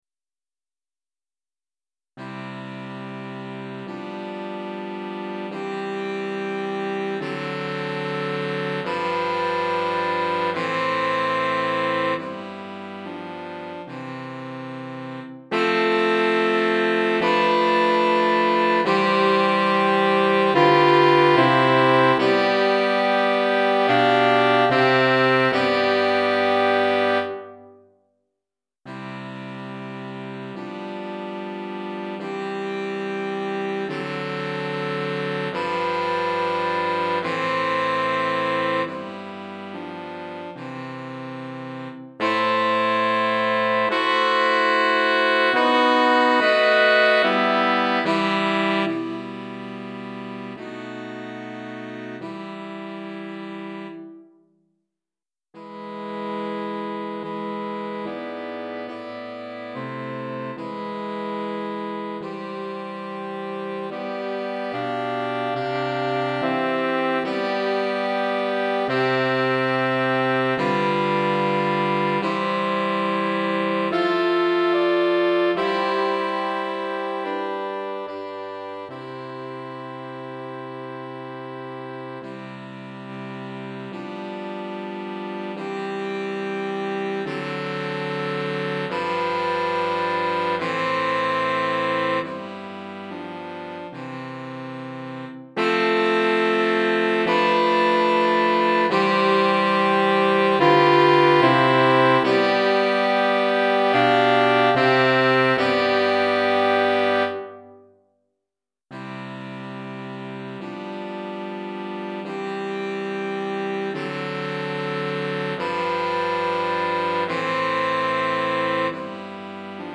Saxophone Soprano, Saxophone Alto, Saxophone Ténor